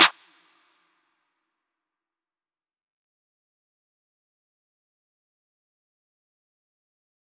Claps
DMV3_Clap 20.wav